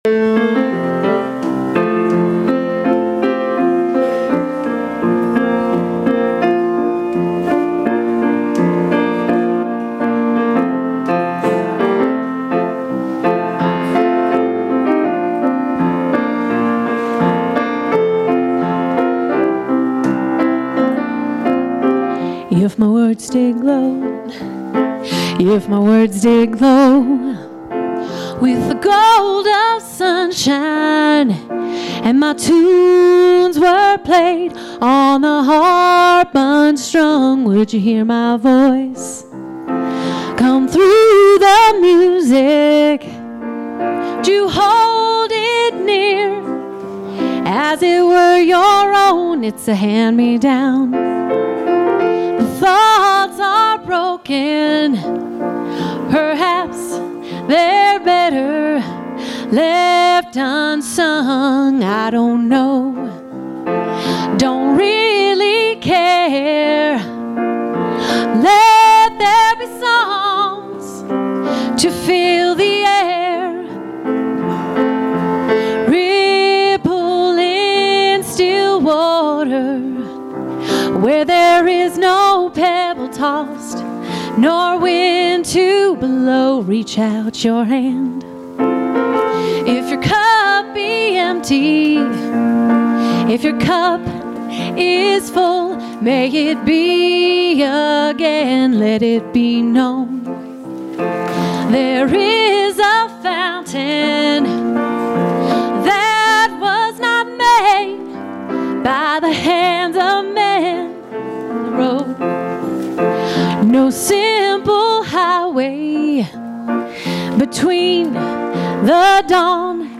Musical Performance Ripple
Sermons from St. John's Episcopal Church